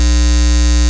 Lo Fi Noize Long-A#2.wav